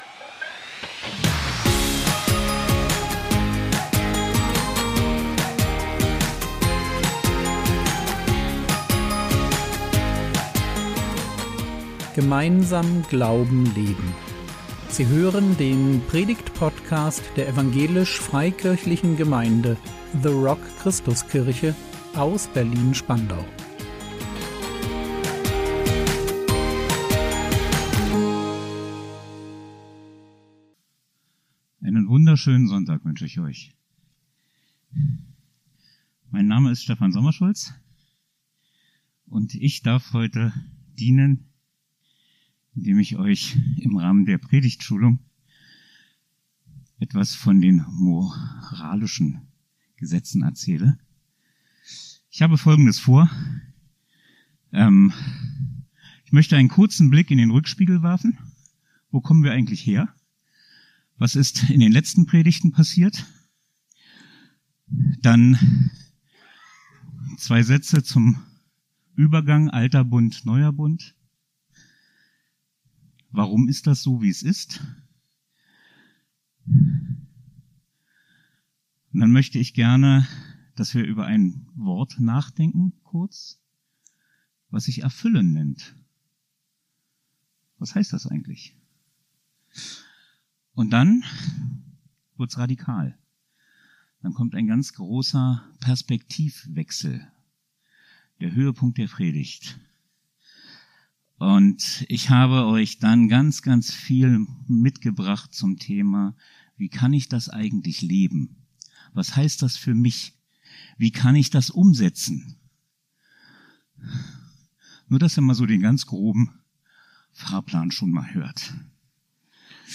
Du sollst nicht stehlen | 06.04.2025 ~ Predigt Podcast der EFG The Rock Christuskirche Berlin Podcast